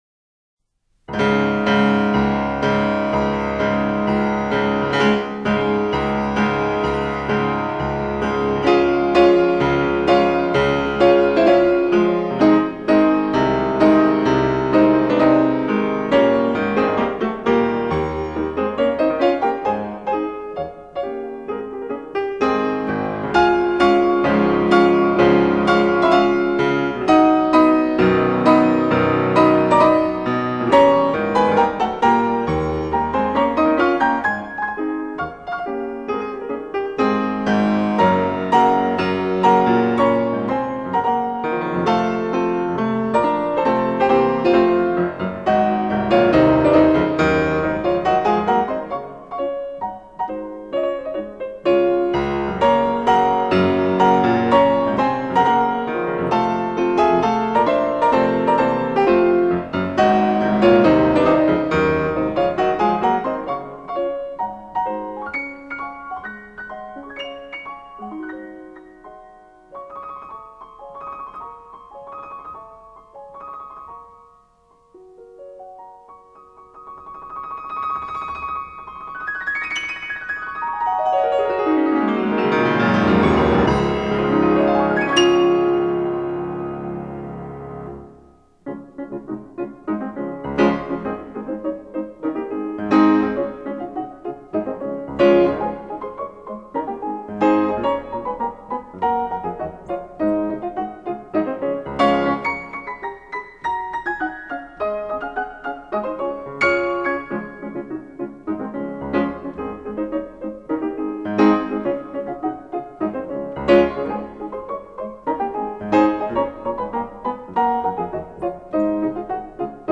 音樂類型：古典音樂